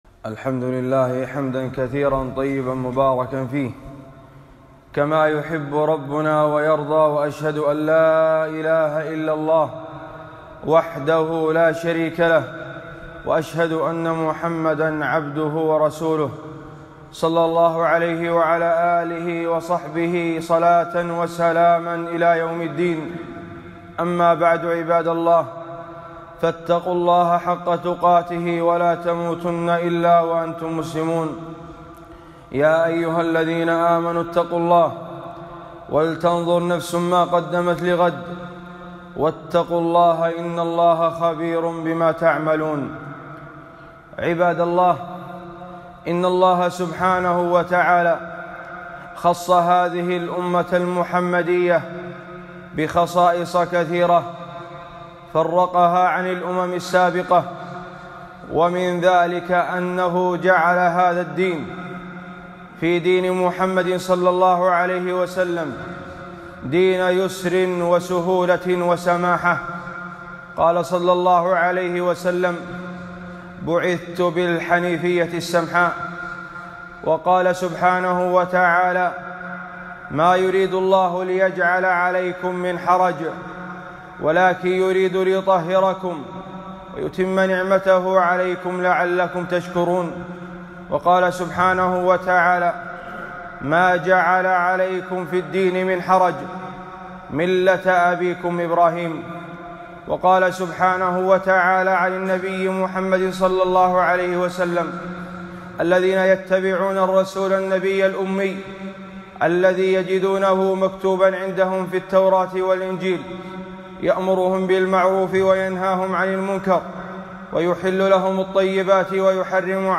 خطبة - المسح على الخفين مسائل وأحكام 3-5-1442